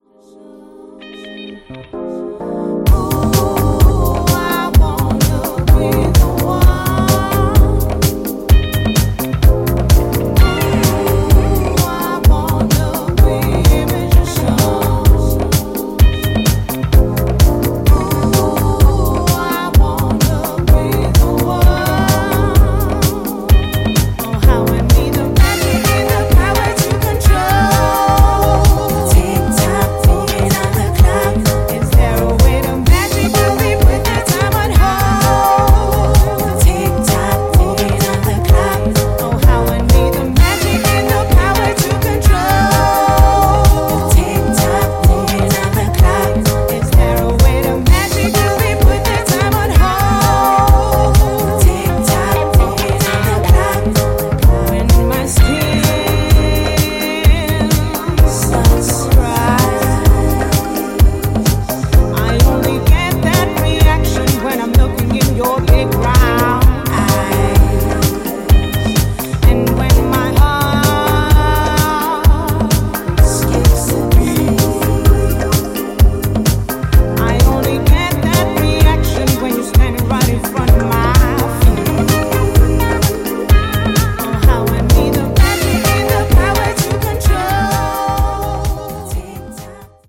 【7"INCH】(レコード)
ジャンル(スタイル) SOULFUL HOUSE / HOUSE